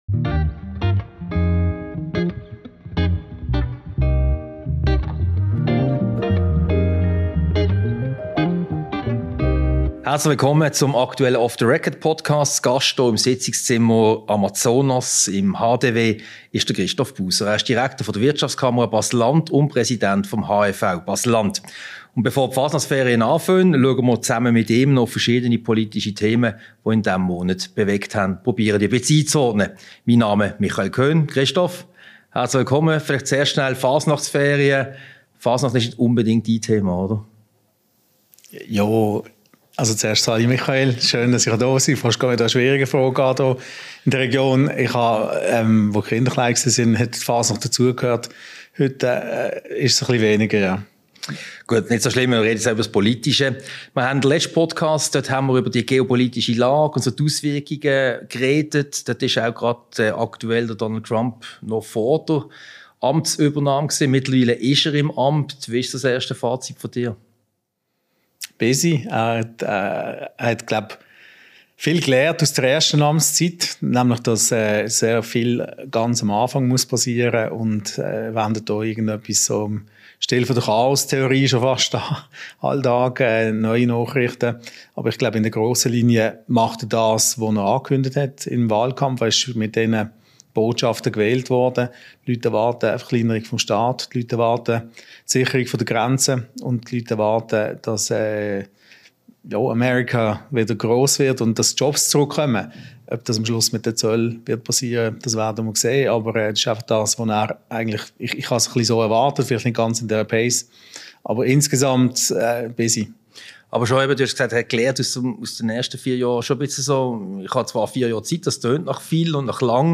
Diese Podcast-Ausgabe wurde als Video-Podcast im Sitzungszimmer Amazonas im Haus der Wirtschaft HDW aufgezeichnet.